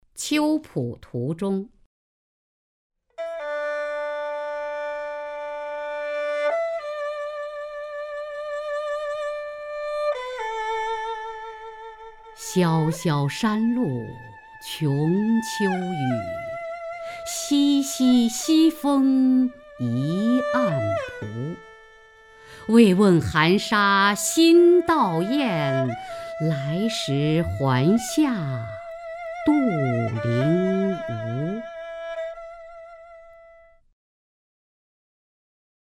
雅坤朗诵：《秋浦途中》(（唐）杜牧) （唐）杜牧 名家朗诵欣赏雅坤 语文PLUS